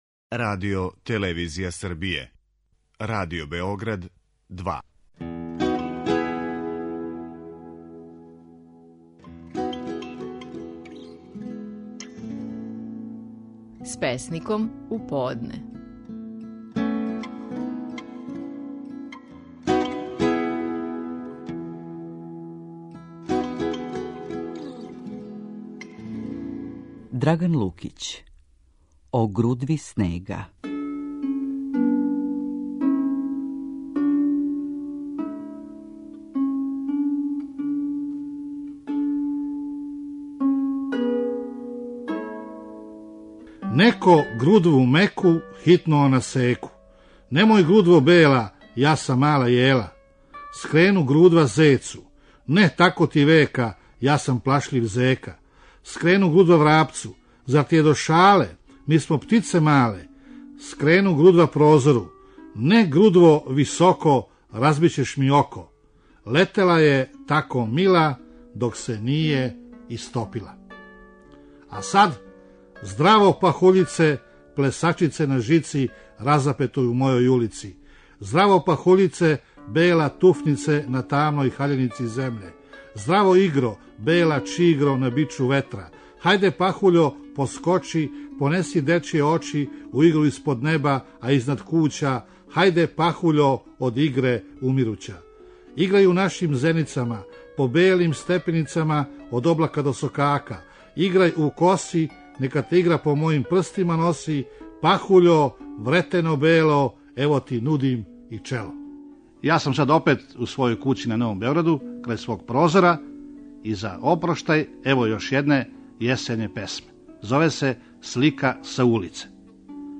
Стихови наших најпознатијих песника, у интерпретацији аутора.
Драган Лукић казује песму „О грудви снега".